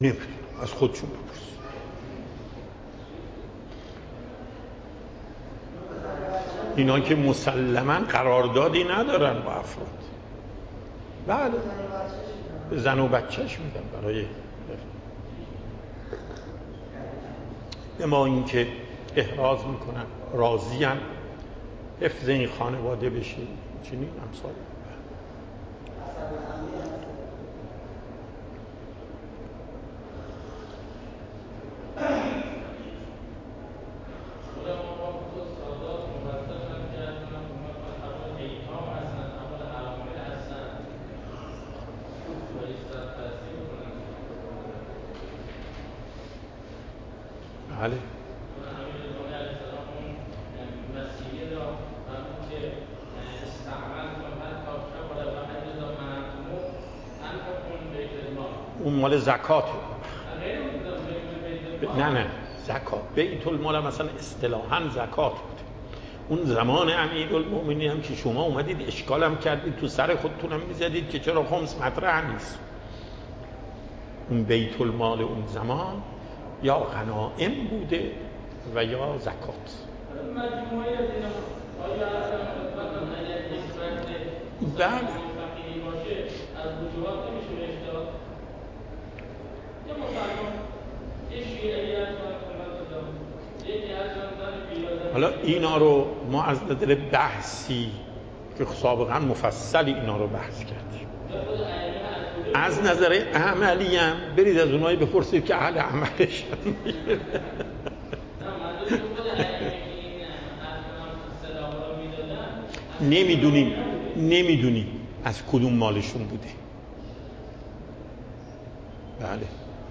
آیت الله سید علی محقق داماد
پخش صوت درس: